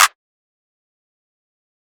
Clap (Metro).wav